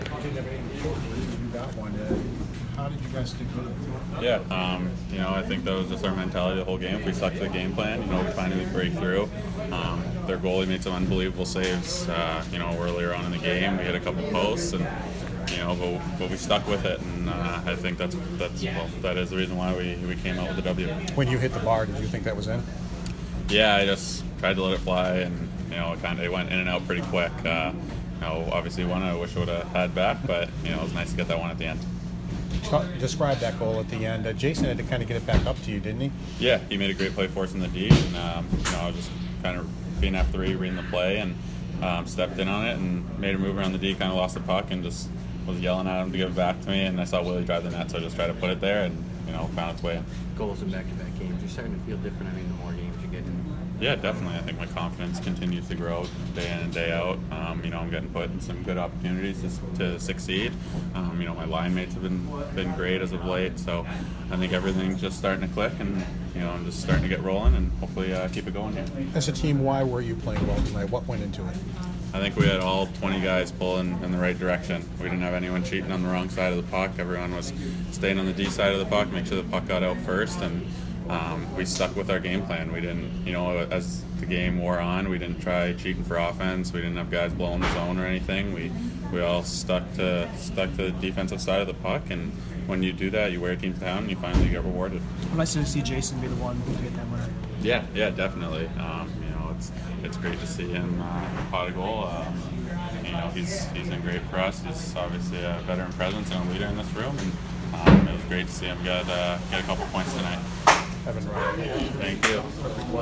Evan Rodrigues post-game 2/28